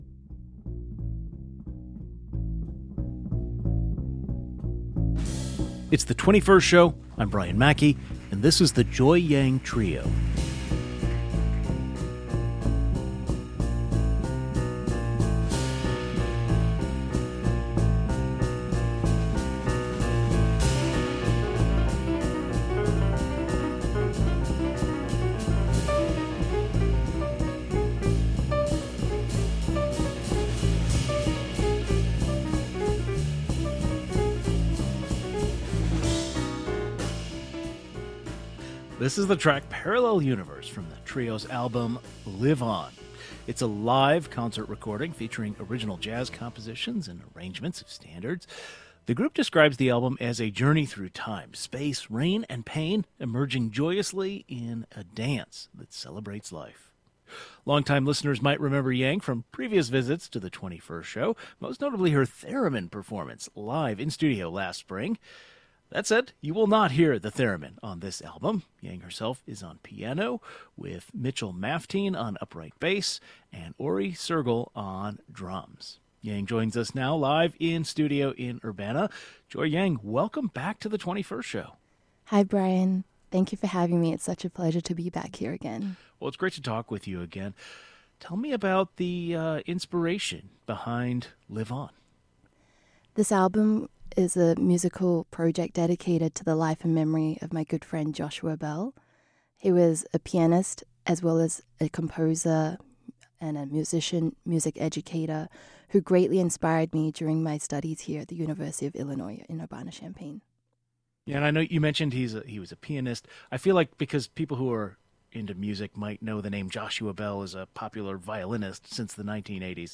jazz piano theremin